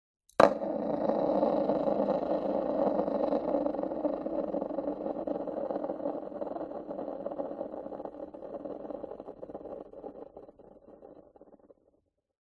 Звук раскрученного волчка на деревянном столе